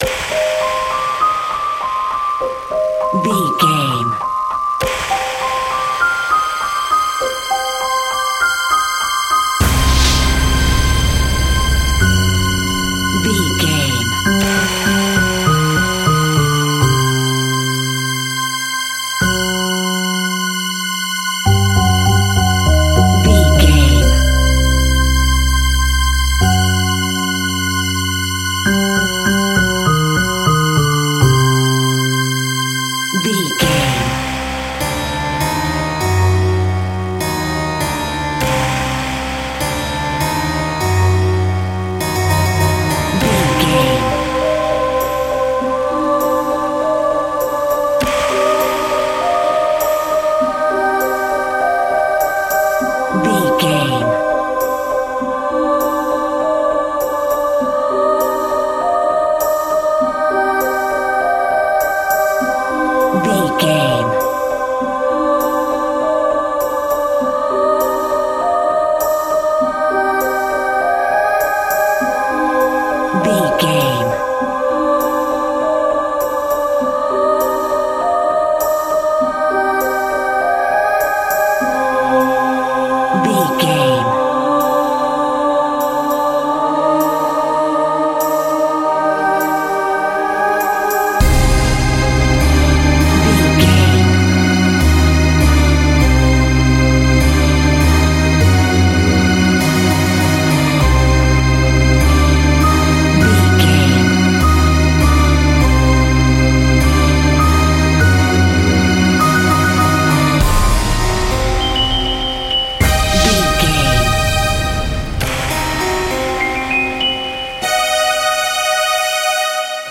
Aeolian/Minor
scary
ominous
dark
suspense
haunting
eerie
strings
percussion
Horror Synths
horror piano
Scary Strings